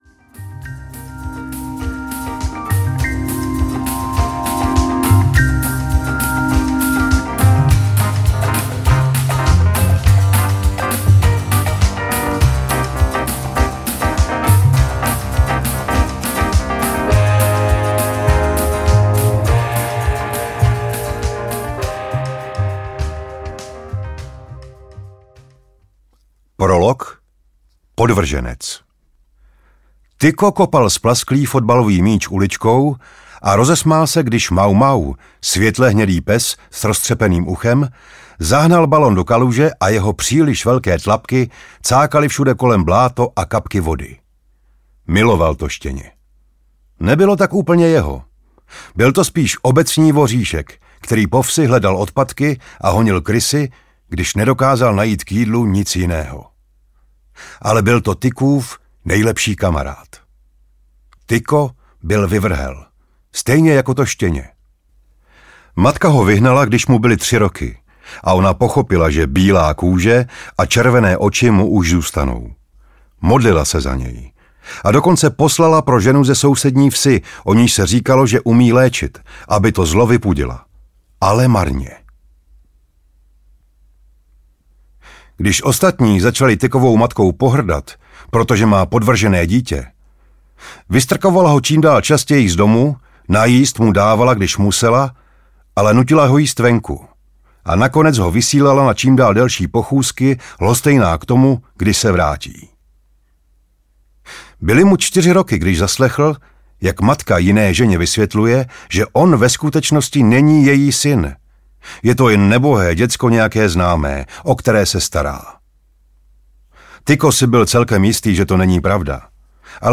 Hračkář audiokniha
Ukázka z knihy
hrackar-audiokniha